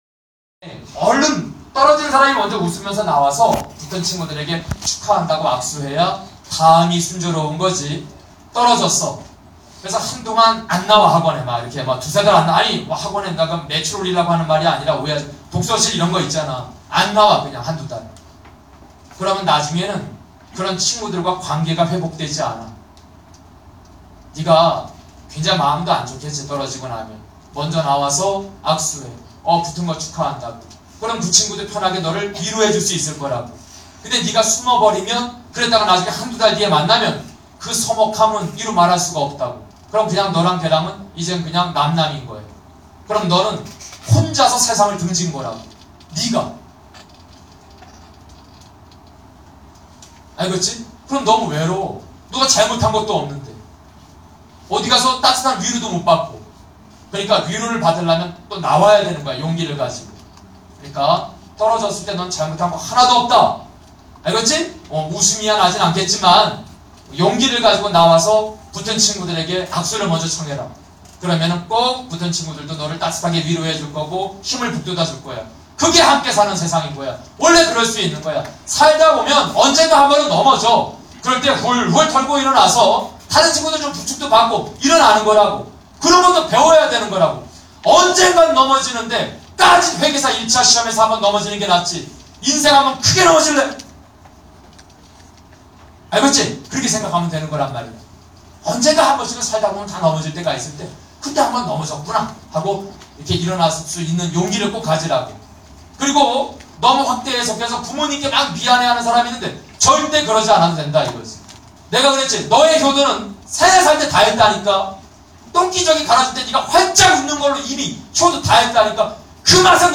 회계사 1차 시험을 앞두고 종강을 하는 마무리 멘트인 것 같은데